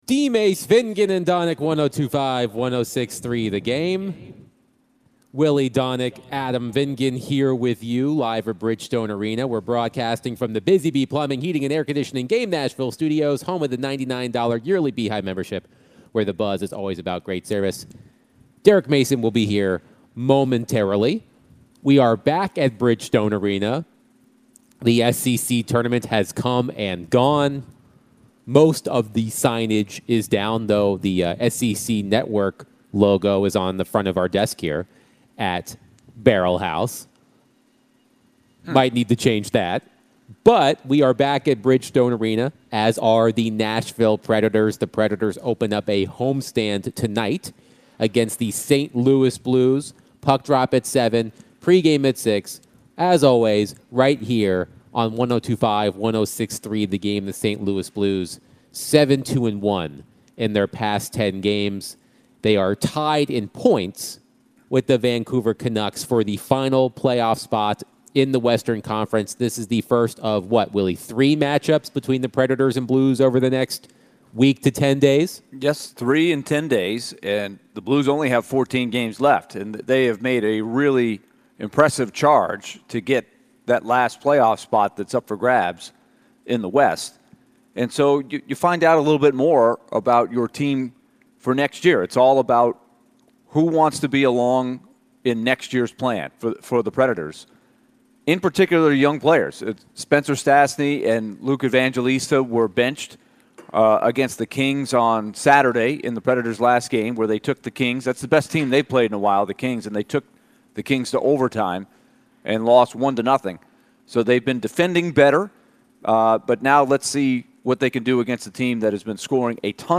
Later in the hour ESPN NFL analyst Tim Hasselbeck joined the show and shared his thoughts about the top prospects in the upcoming NFL Draft.